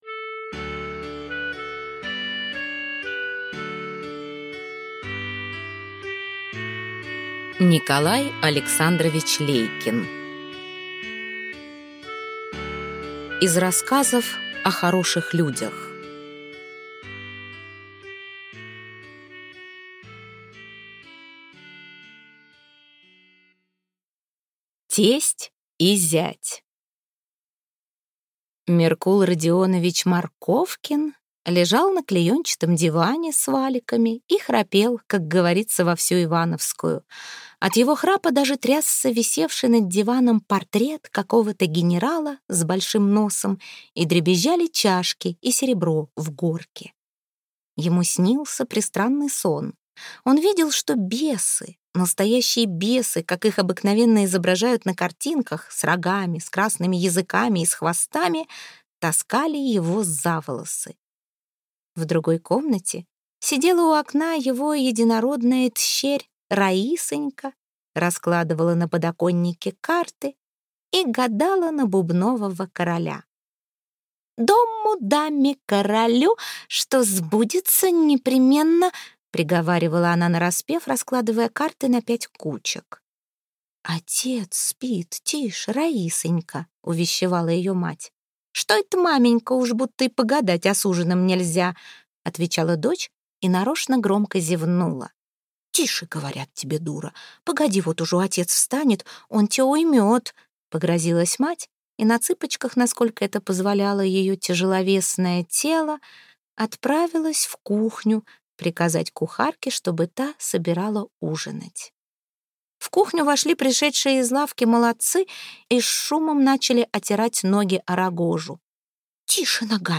Аудиокнига Сборник рассказов | Библиотека аудиокниг